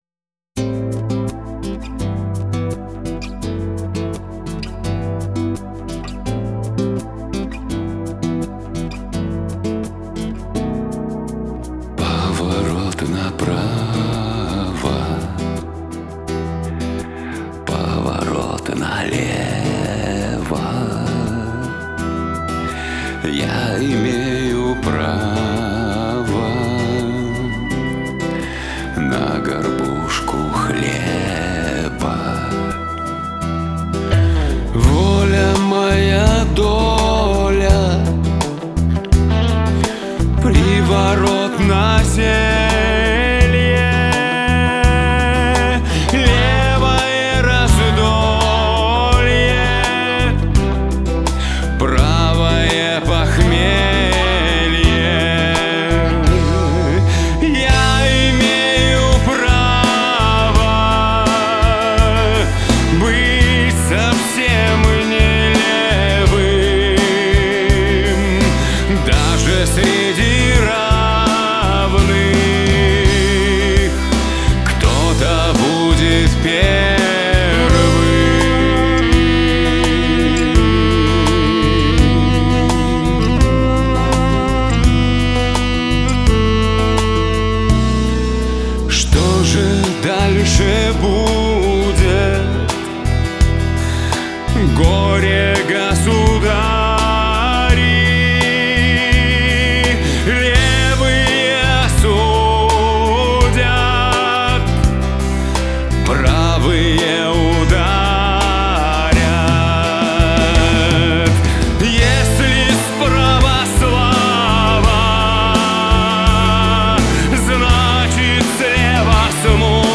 Песню жалобную поет...:)